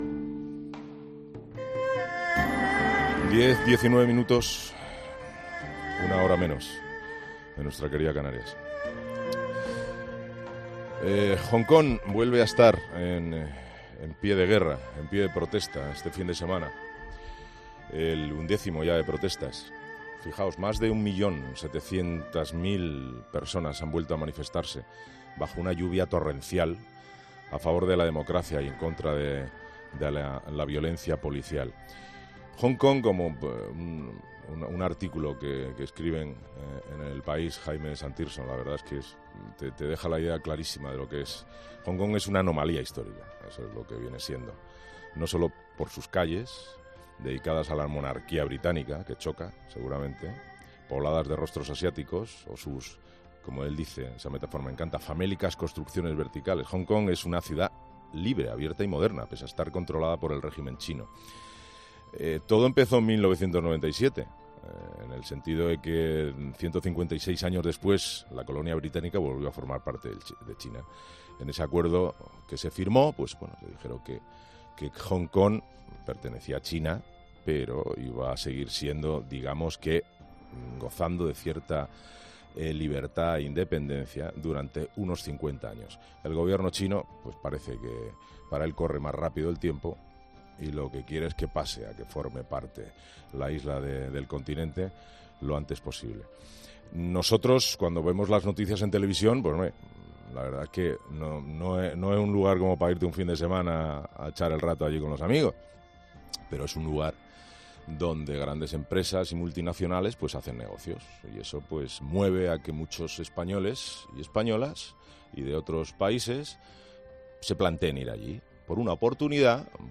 Dos jóvenes españoles que viajarán al país asiático para continuar allí sus estudios nos cuentan cuáles son sus expectativas